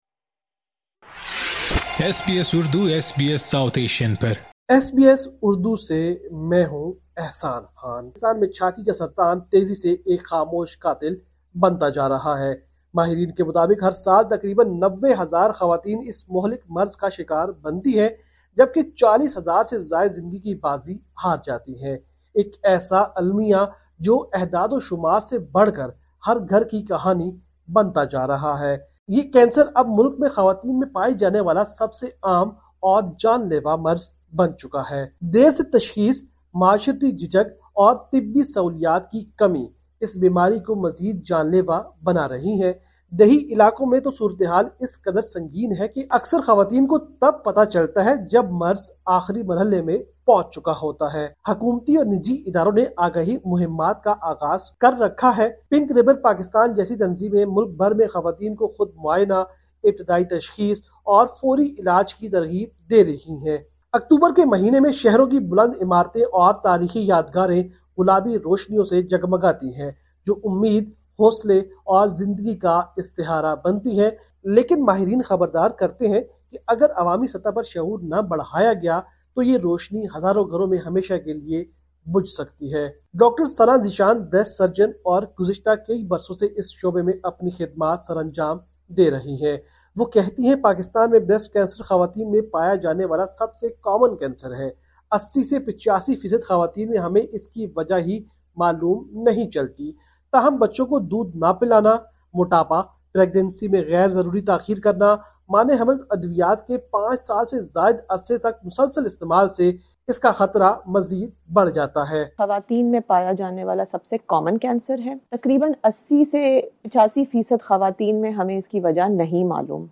پاکستان رپورٹ